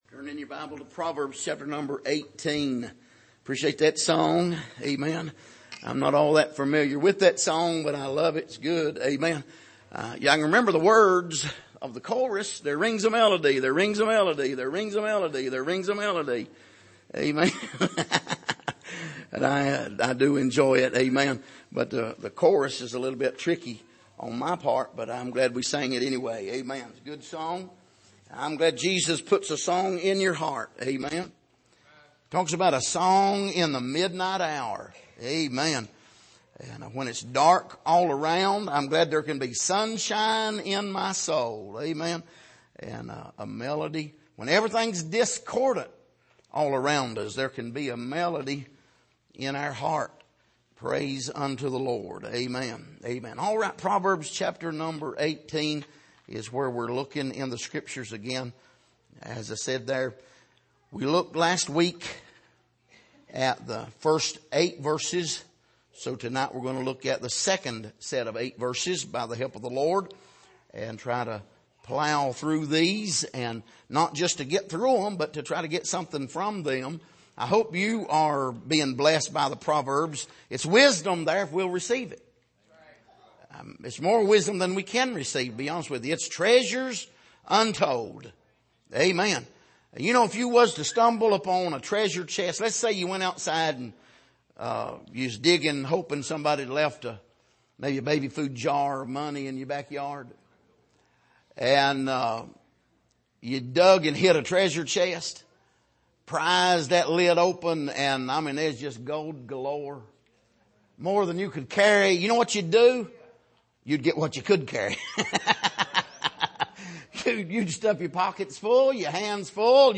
Passage: Proverbs 18:9-16 Service: Sunday Evening